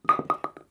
bowlingPinFall_4.wav